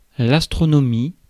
Ääntäminen
France: IPA: [l‿as.tʁo.no.miː]